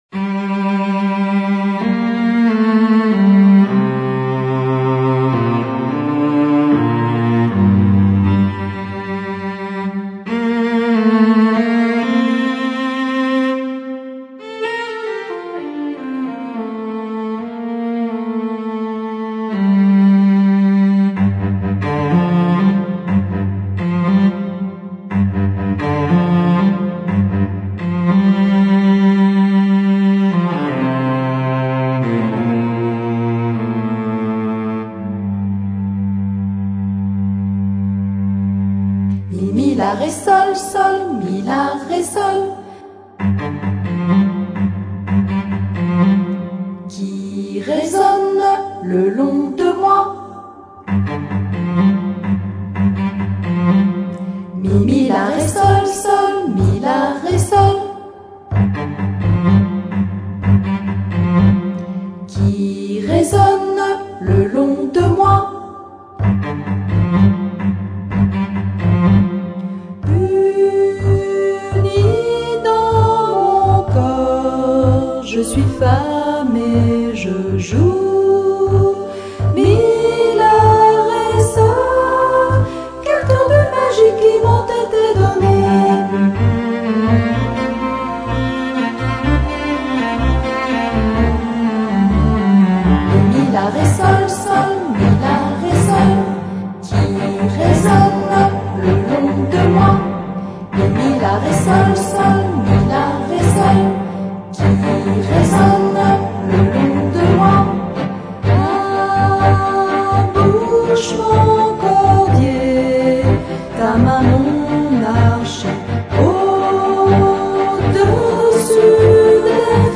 Sculpture sonore